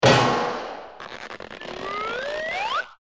WB1.32.1 BigHeadHit&BumpRise.mp3